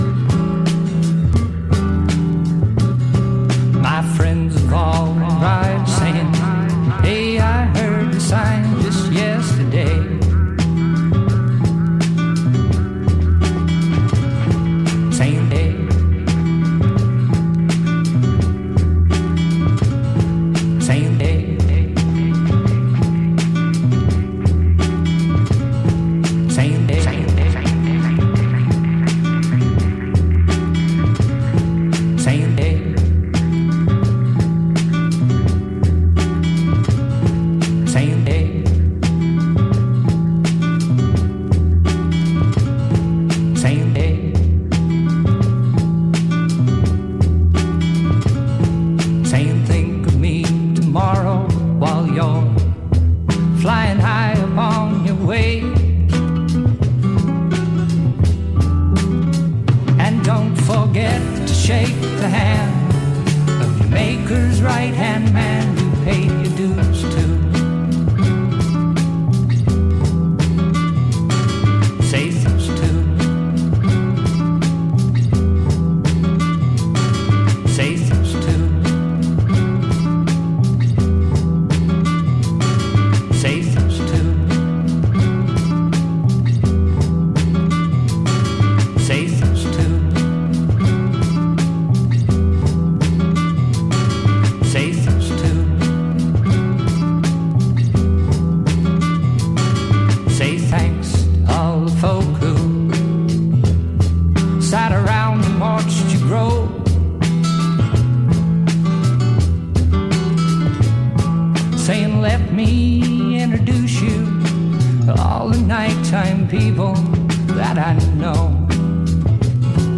Balearic
edits
gentle guitar strums